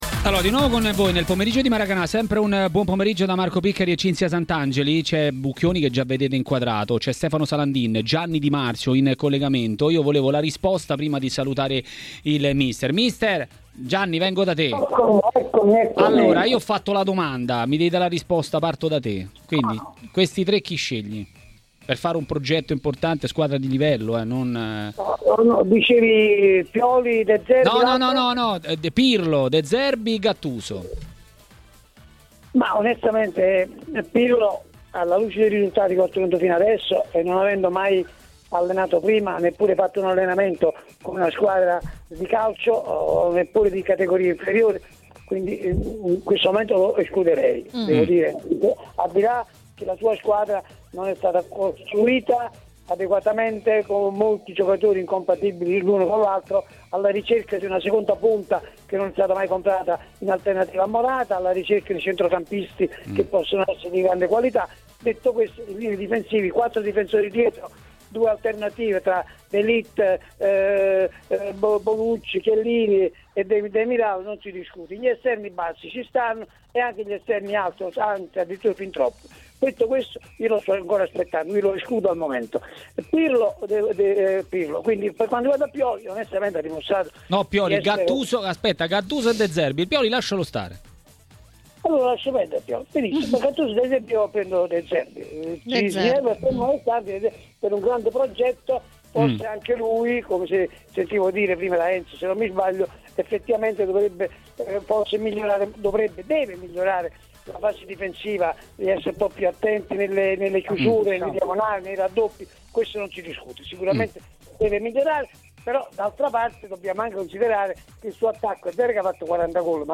nel pomeriggio di TMW Radio, ha parlato del caso Lazio-Torino.